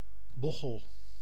Ääntäminen
France: IPA: /bɔs/